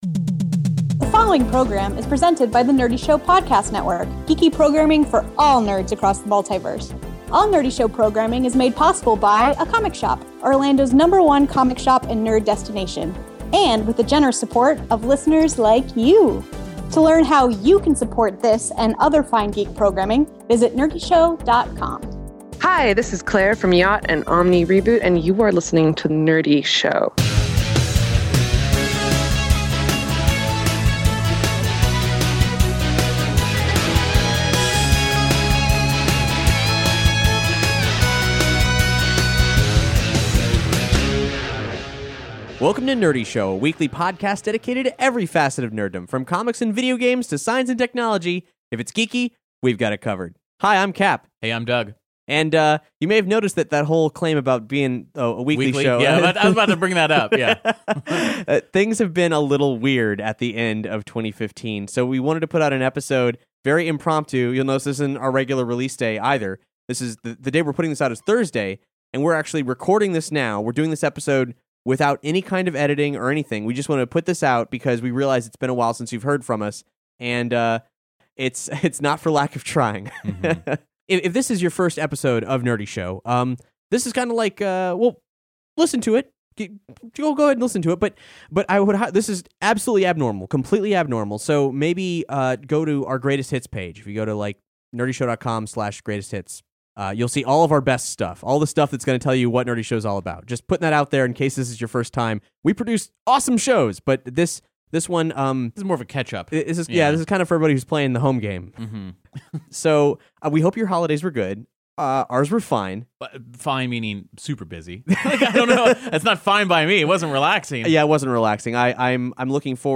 Before we return to your regularly scheduled Nerdy Show, we wanted to do an impromptu community update explaining where we've been, where we are, and where we're headed.